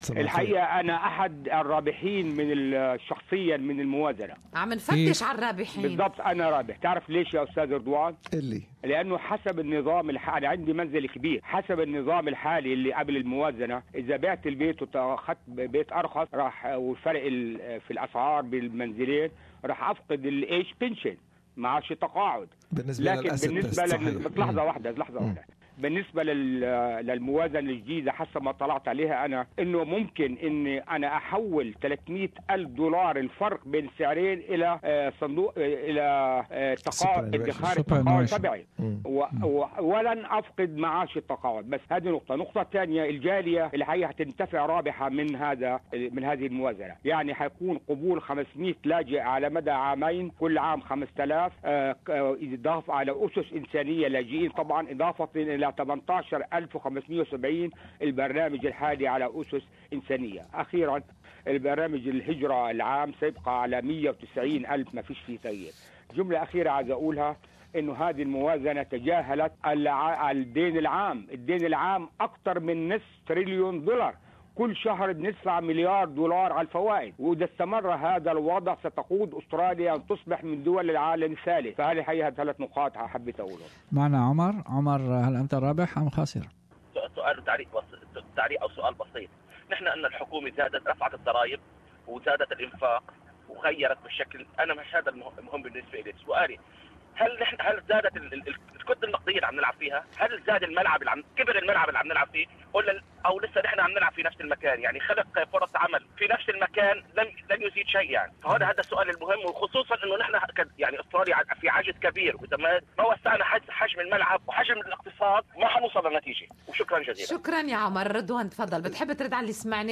في خضم الزوبعة التي عرفتها أستراليا غداة الاعلان عن الميزانية 2017-2018 التي أطلقتها الحكومة الفدرالية يوم 9 أيار/مايو 2017 تحدث مستمعو برنامج Good Morning Australia مبدين رأيهم بشأن الميزانية: